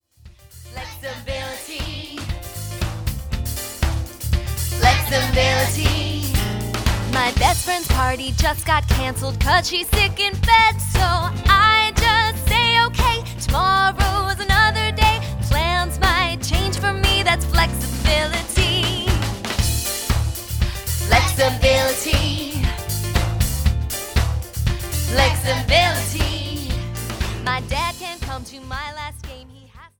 The musical arrangement is perfect for lots of movement.
• MP3 of both vocals and instrumental.